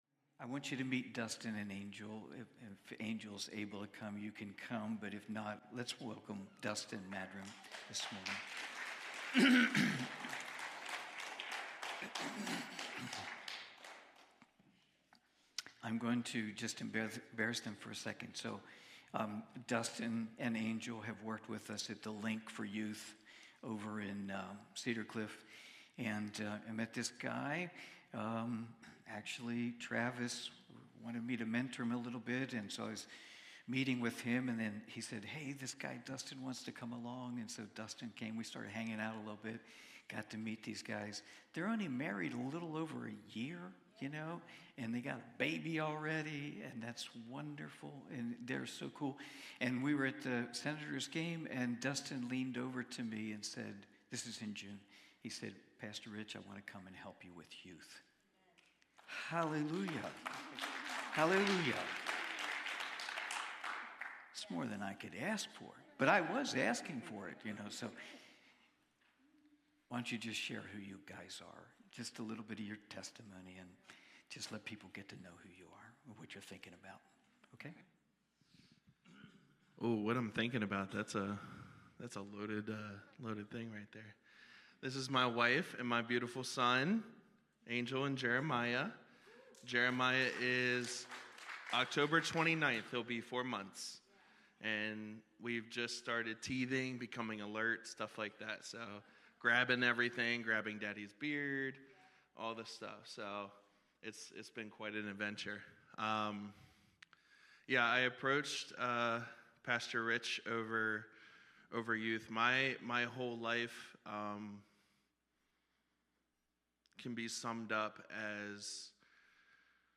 Part two of the 10/13/2024 Cornerstone Fellowship Sunday morning service, livestreamed from Wormleysburg, PA.